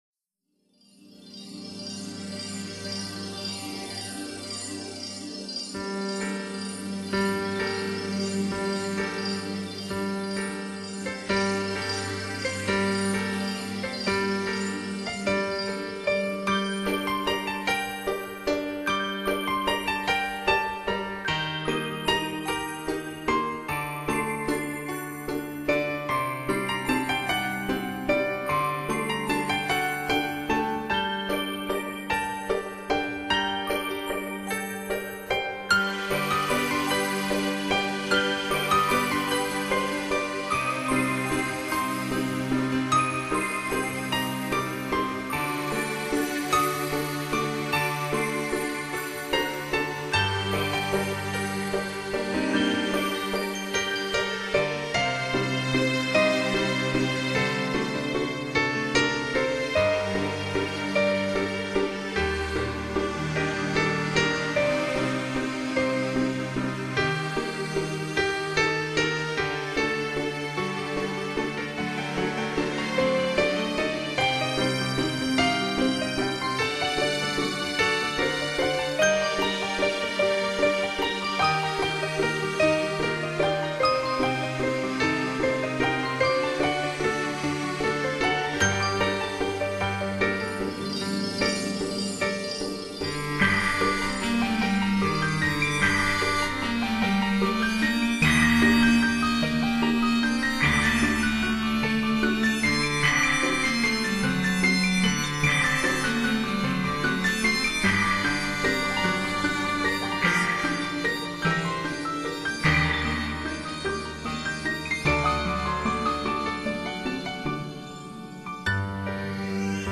天籁发烧盘，精选11首混声合唱好歌，
加上琴声环绕，给人以极致享受。
24bit/96khz的多轨数码录音，